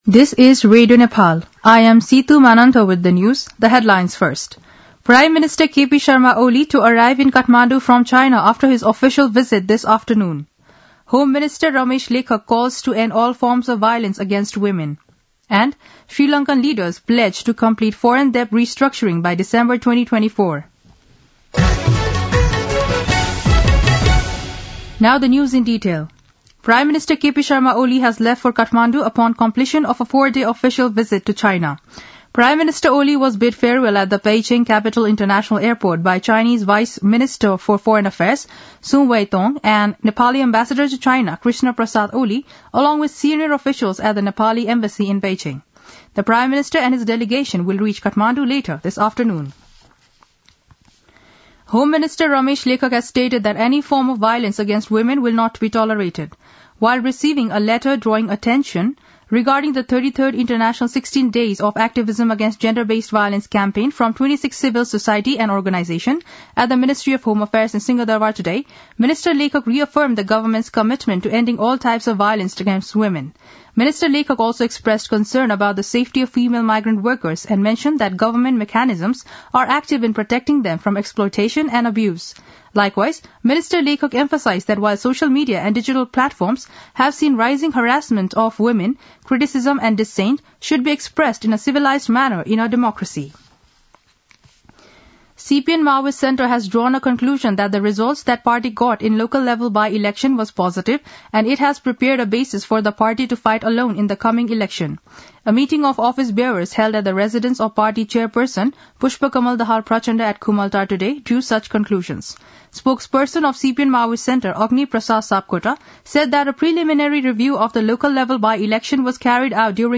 दिउँसो २ बजेको अङ्ग्रेजी समाचार : २१ मंसिर , २०८१
2-pm-english-news-1-4.mp3